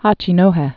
(hächē-nōhĕ)